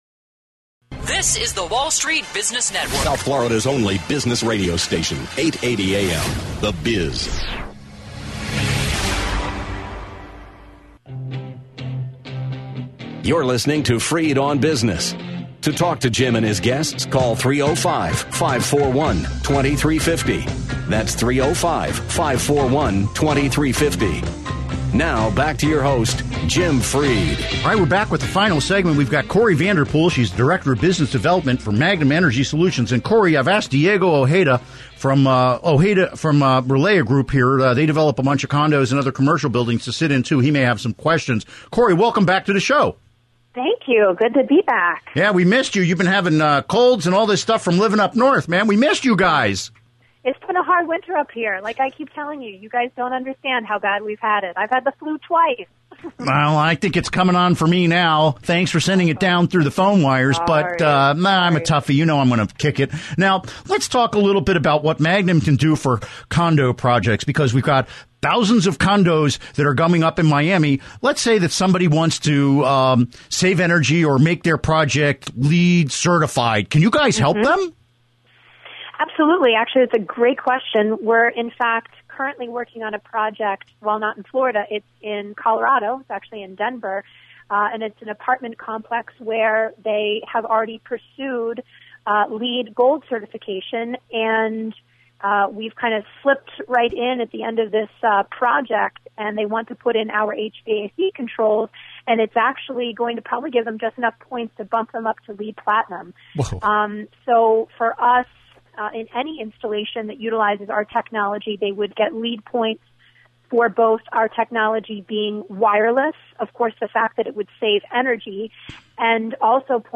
Interview Segment